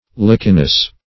lichenous - definition of lichenous - synonyms, pronunciation, spelling from Free Dictionary
(l[imac]"k[e^]n*[u^]s)